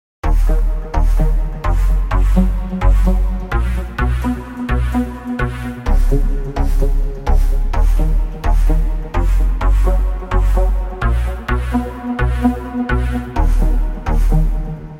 光 BASS SEQ 128
Tag: 128 bpm House Loops Bass Synth Loops 4.12 MB wav Key : A